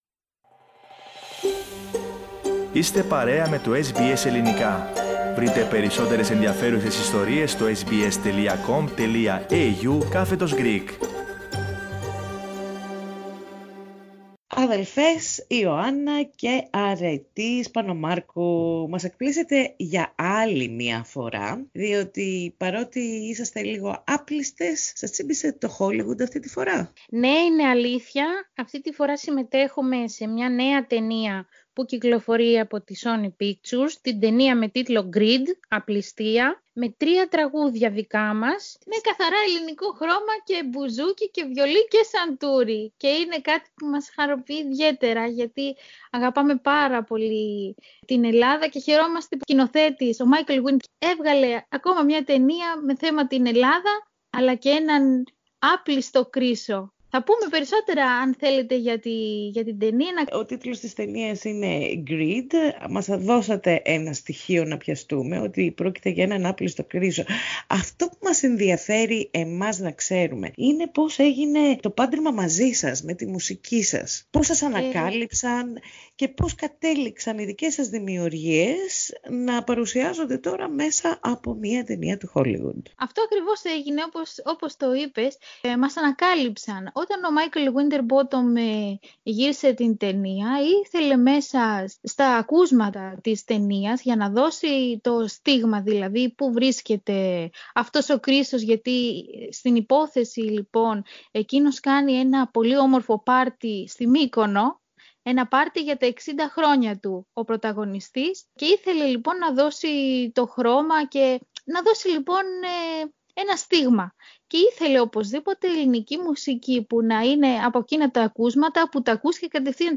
Ακούστε τί είχαν να πουν στο Ελληνικό πρόγραμμα της ραδιοφωνίας SBS.